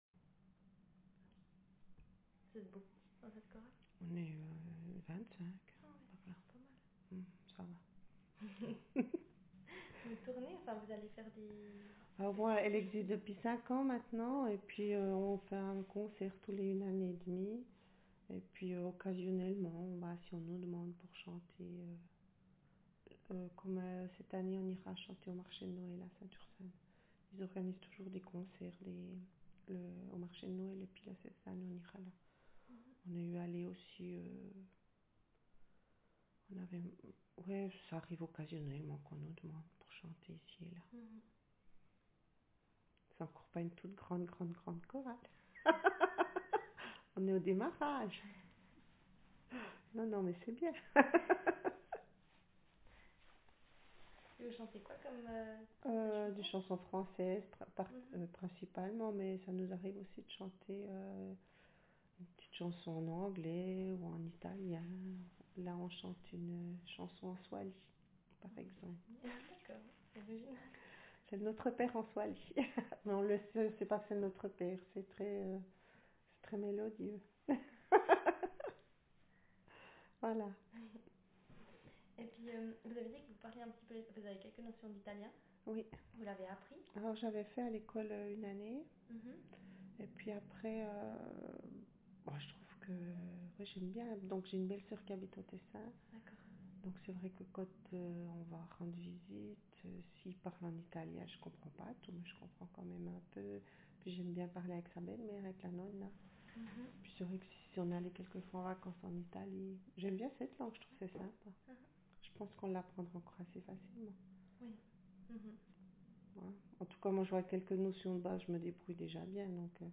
DoReCo - Language French (Swiss)
Speaker sex f Text genre personal narrative